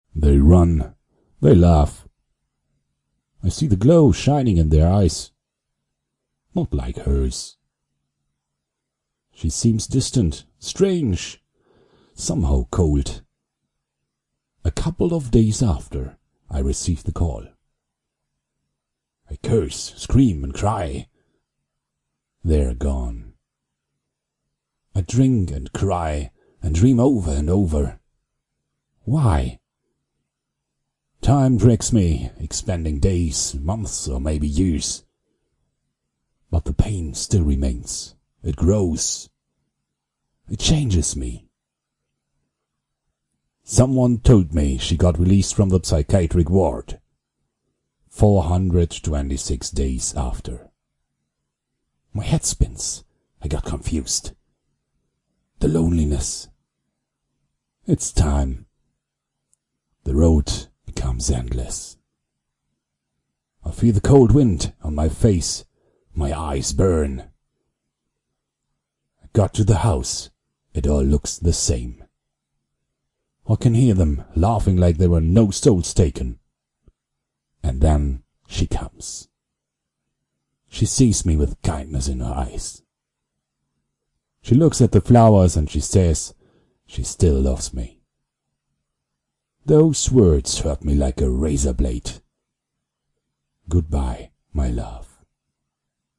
man1.mp3